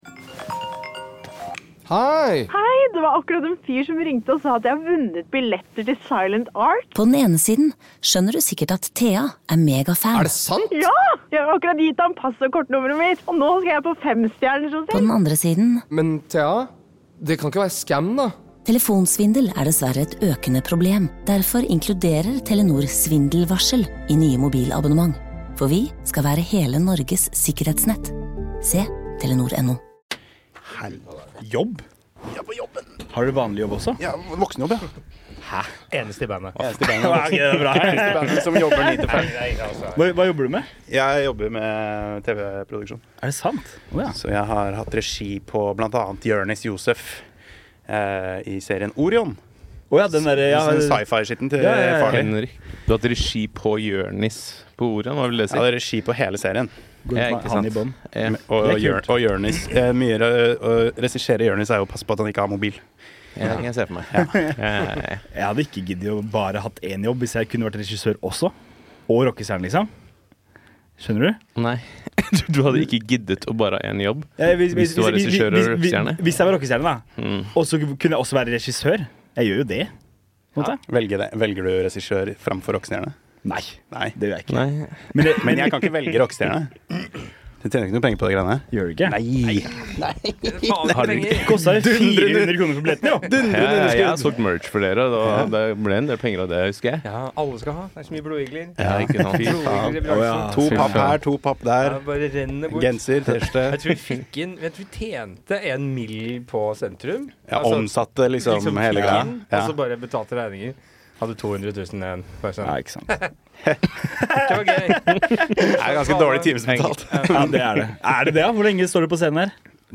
Bare en gjeng rockere som samles for litt rockeprat.